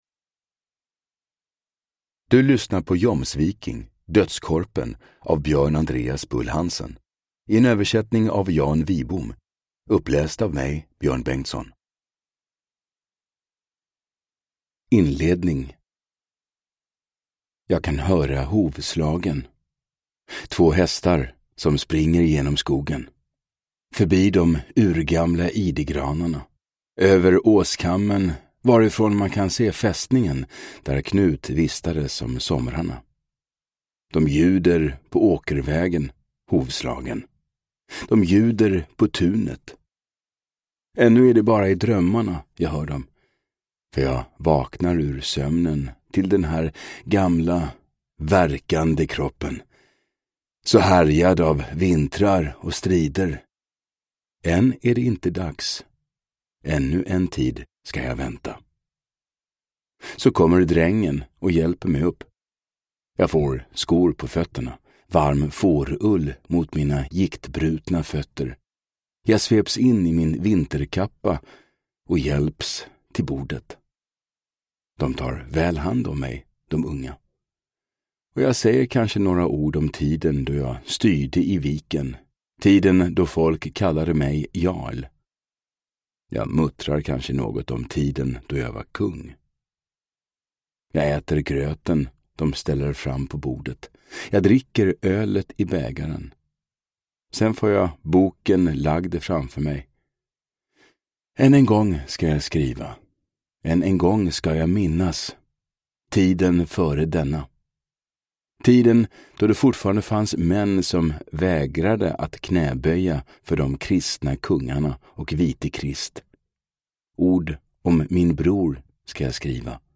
Jomsviking. Dödskorpen – Ljudbok